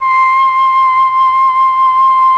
RED.FLUT1 30.wav